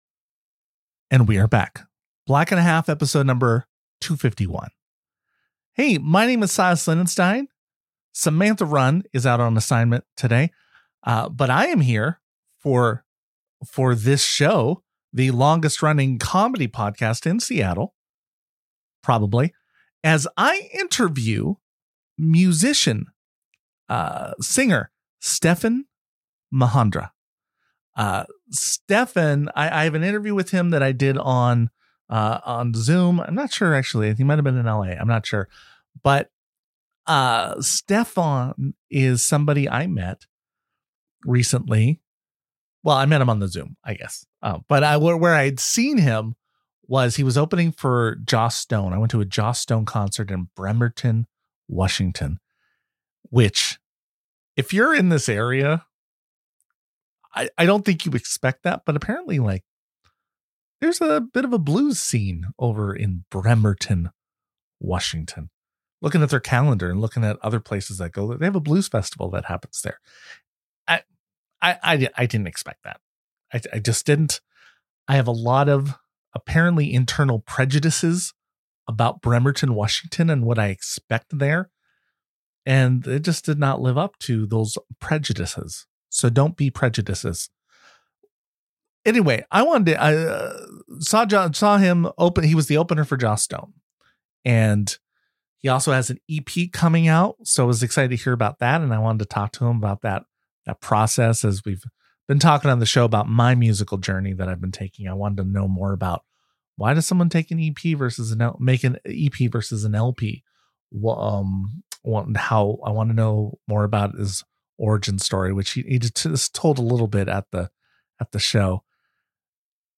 It’s a thoughtful, funny, and honest conversation about creativity, vulnerability, and the process of getting better at something that matters to you.